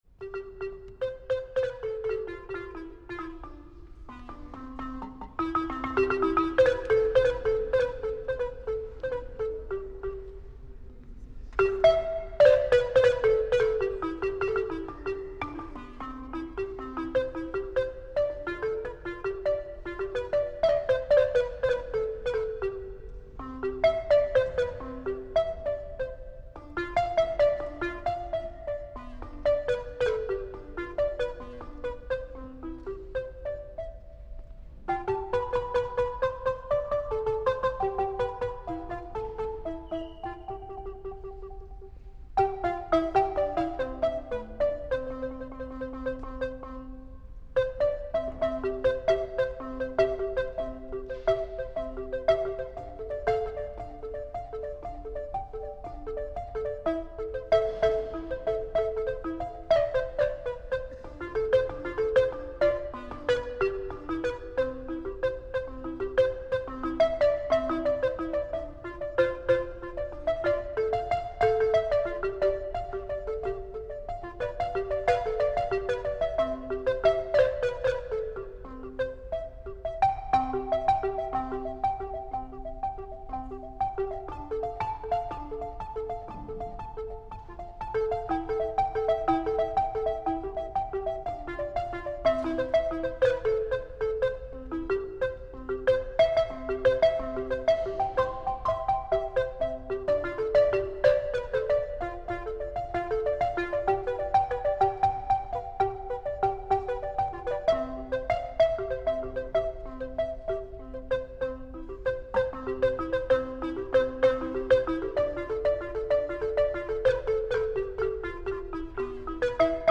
vibraphoniste et balafoniste
Concert
Bibliothèque d'étude et du patrimoine, Toulouse le 28 mars 2025
vibraphone
balafon. Il a notamment interprété le prélude de la 1ère suite pour violoncelle de Jean-Sébastien Bach, en respectant la partition originale en mode majeur, puis en l’adaptant en mineur.